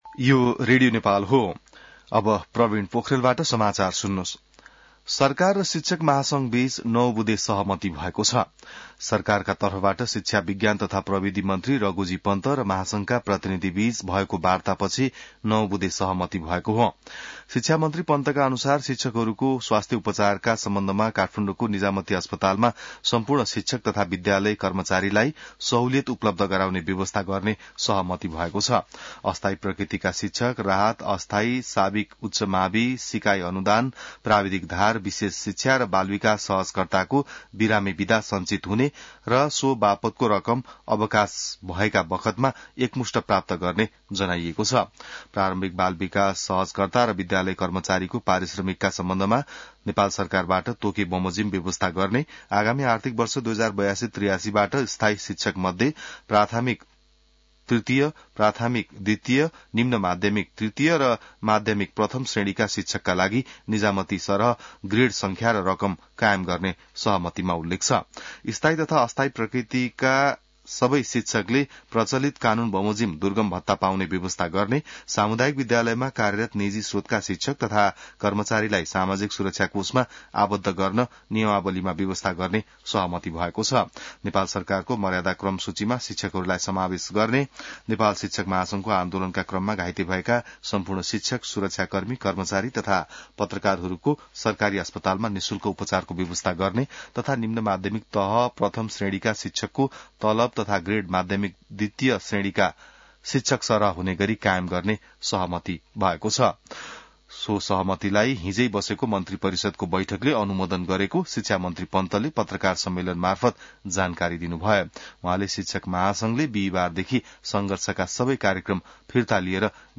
बिहान ६ बजेको नेपाली समाचार : १८ वैशाख , २०८२